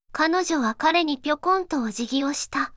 voicevox-voice-corpus
voicevox-voice-corpus / ita-corpus /四国めたん_セクシー /EMOTION100_018.wav